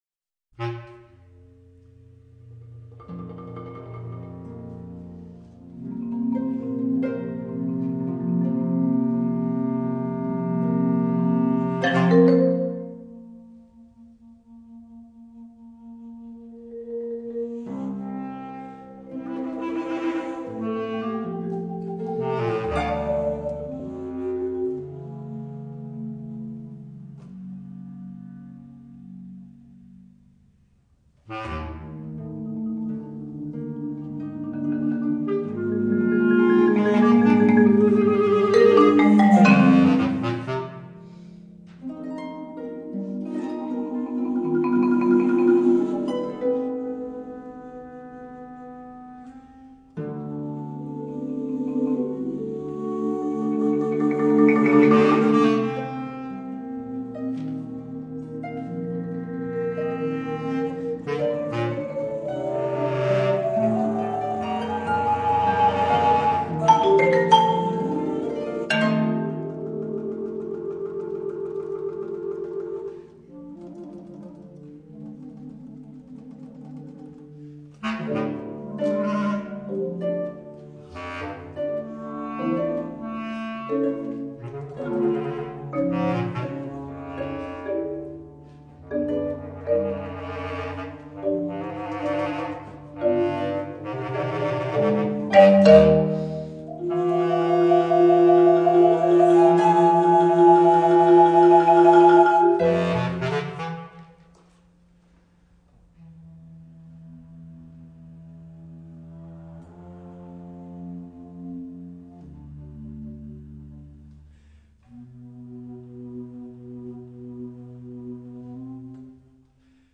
bass flute
bass clarinet
marimba
harp   presentazione